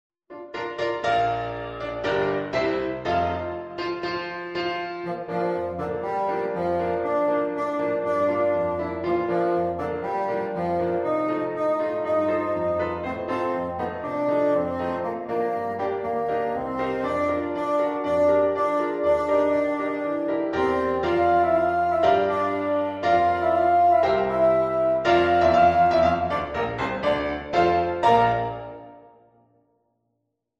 Bassoon and Piano